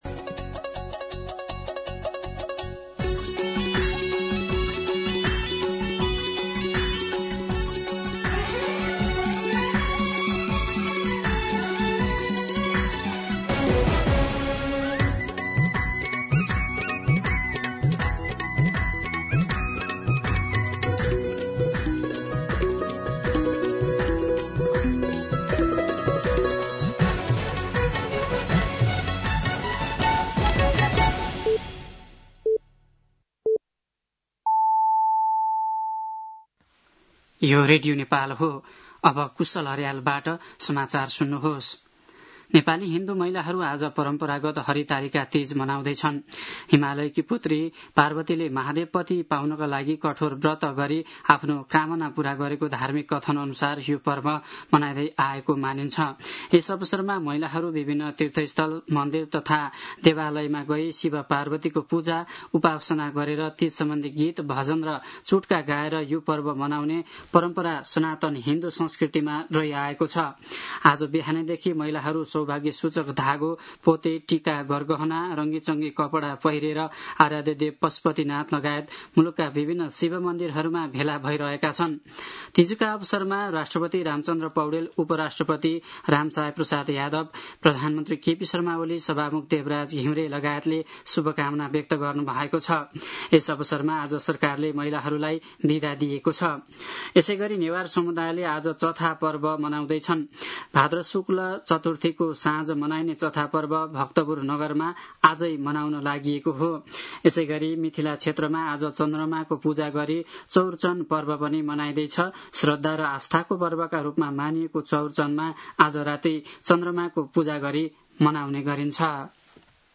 दिउँसो ४ बजेको नेपाली समाचार : १० भदौ , २०८२